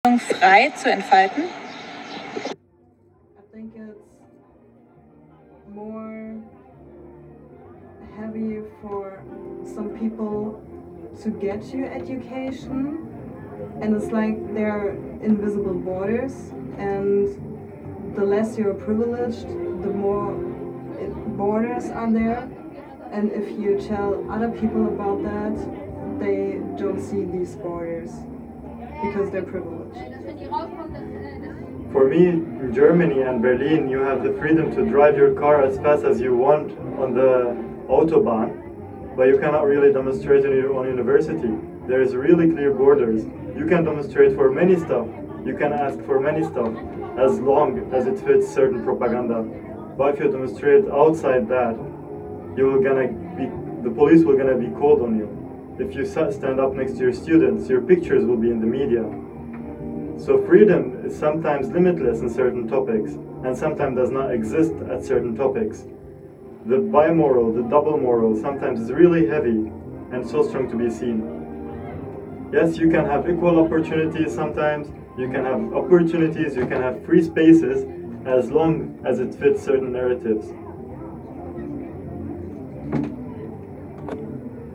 Standort der Erzählbox:
Off University / Lange Nacht der Wissenschaften @ Berlin